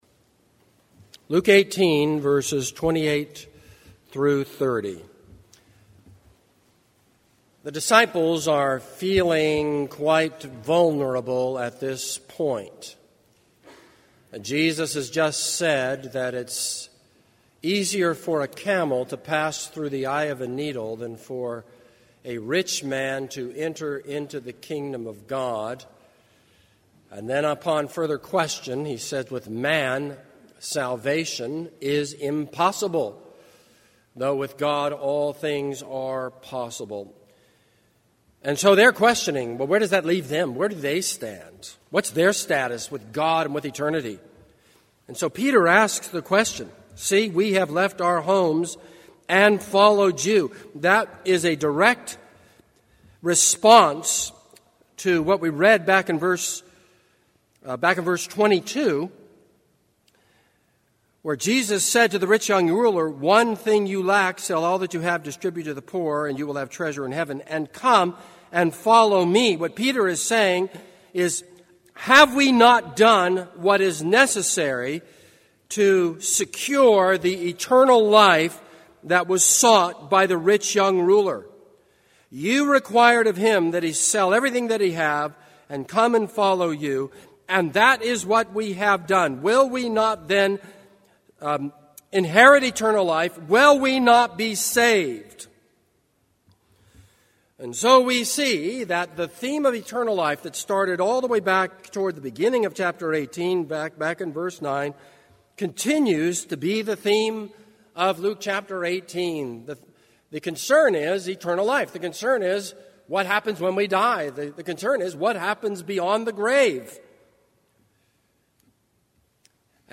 This is a sermon on Luke 18:28-30.